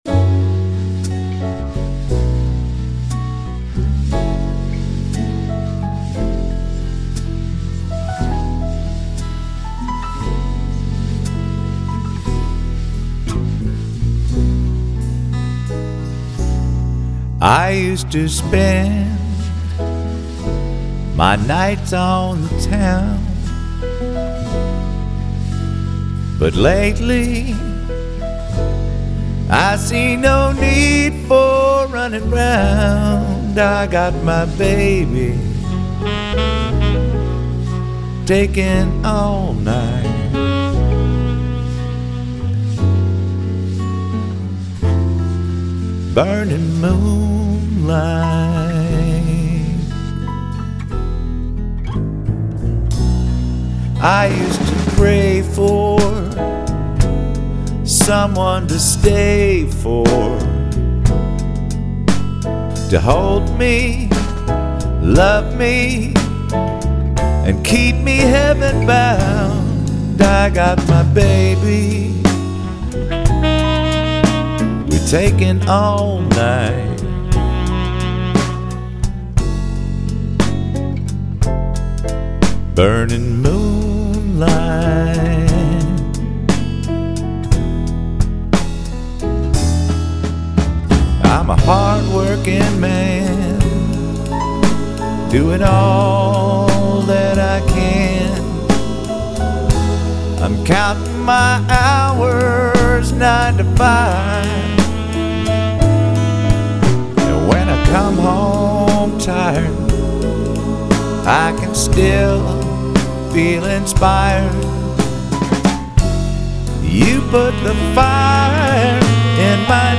Country / Reggae